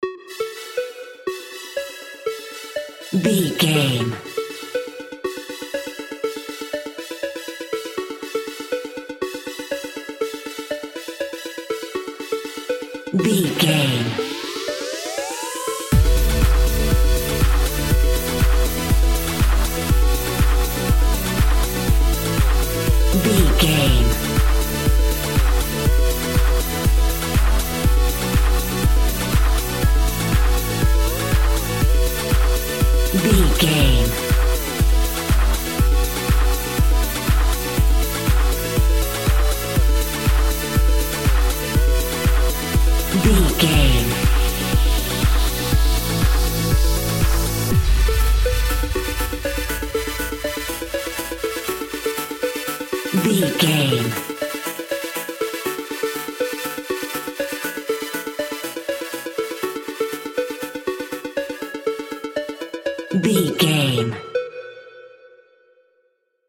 Aeolian/Minor
F#
groovy
uplifting
futuristic
driving
energetic
repetitive
synthesiser
drum machine
house
electro dance
electronic
synth leads
synth bass
upbeat